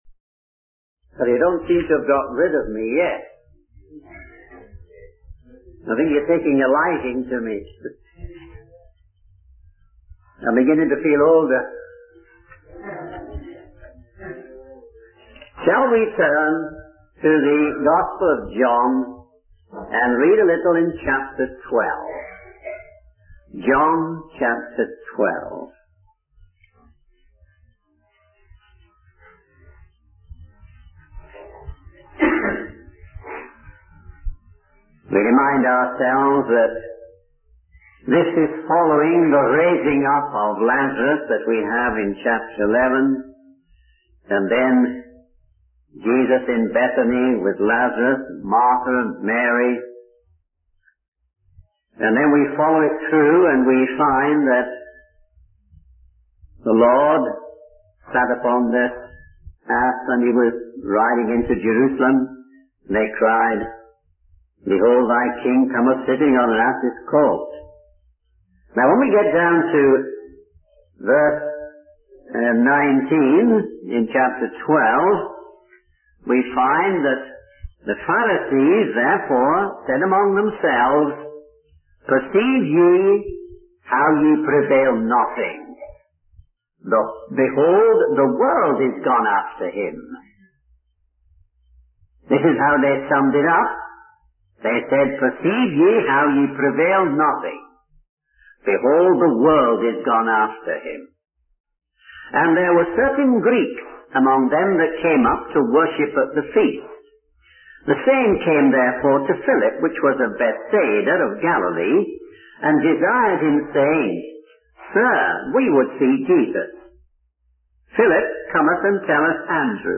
In this sermon, the speaker reflects on the words 'Sir, we would see Jesus' spoken by certain Greeks who came to worship.